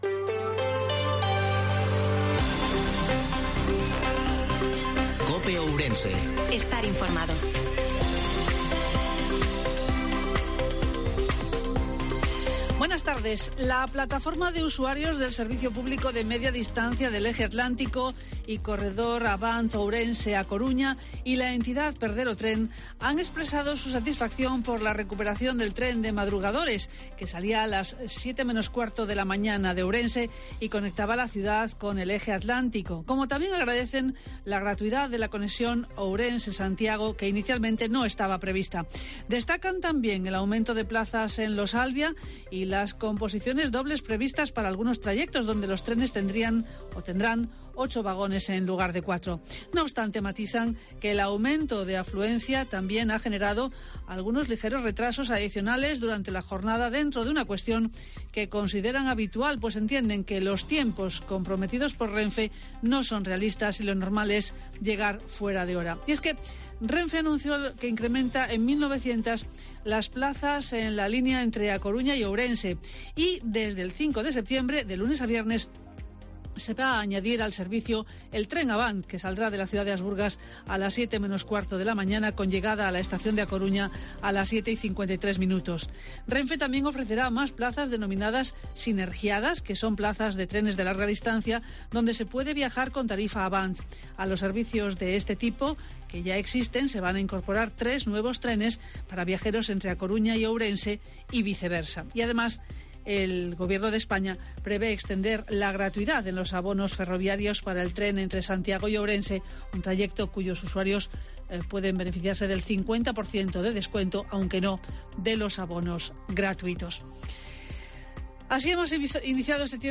INFORMATIVO MEDIODIA COPE OURENSE-01/09/2022